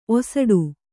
♪ osaḍu